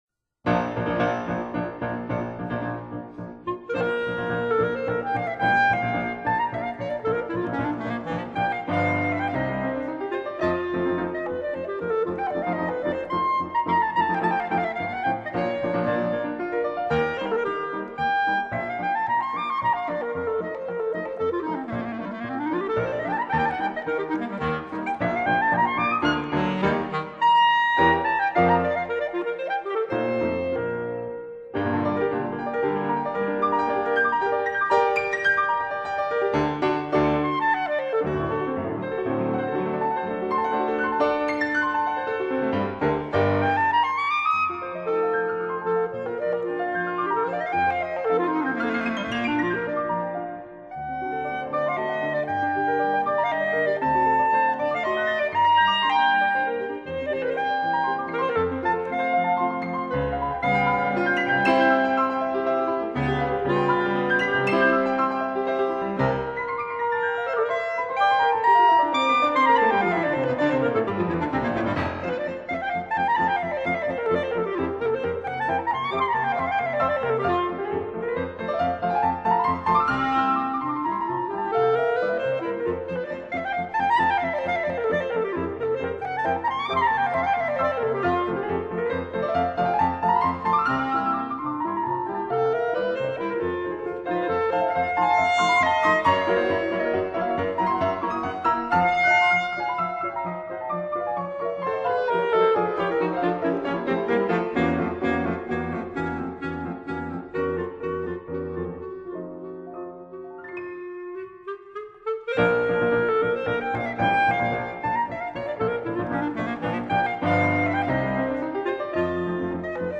for clarinet & piano: Tempo Di Polacco